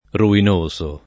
ruinoso [ ruin 1S o ]